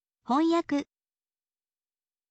hon yaku